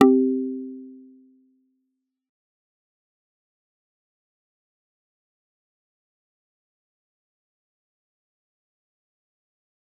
G_Kalimba-C4-f.wav